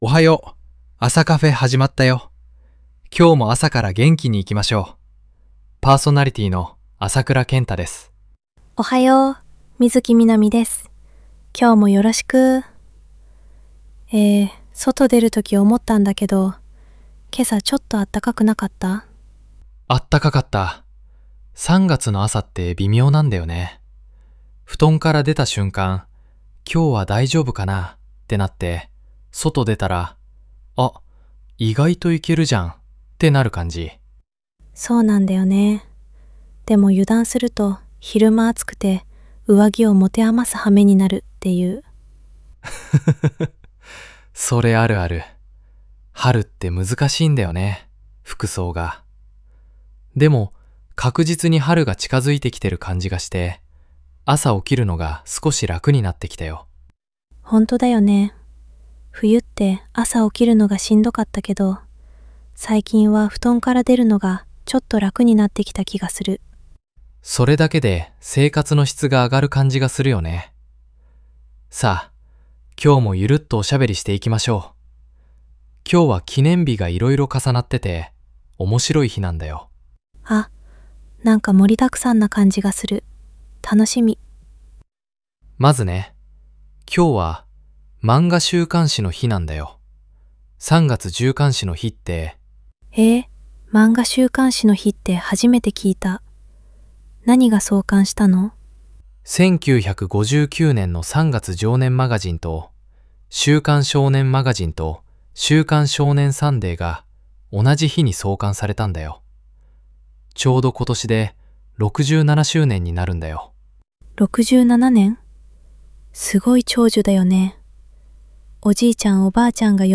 ラジオパーソナリティ